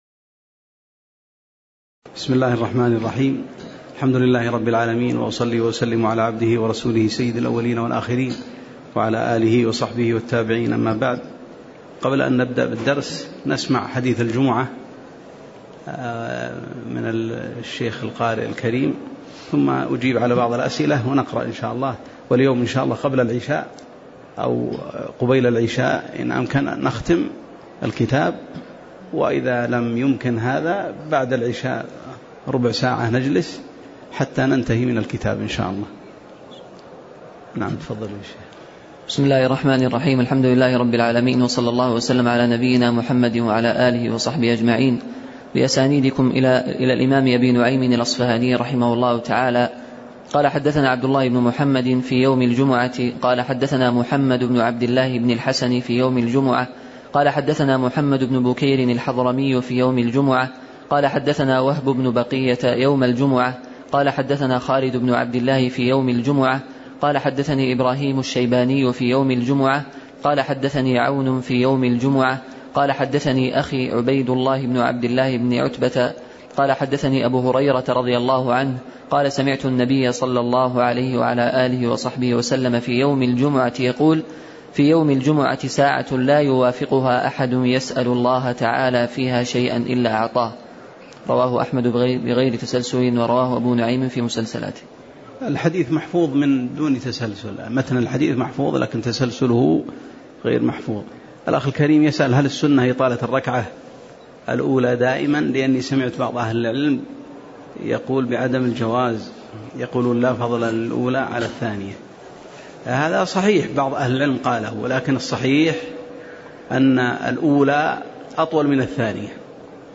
تاريخ النشر ٢٠ محرم ١٤٣٨ هـ المكان: المسجد النبوي الشيخ